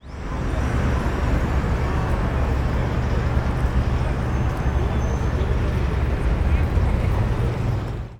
City-Noise-Ambient-3.wav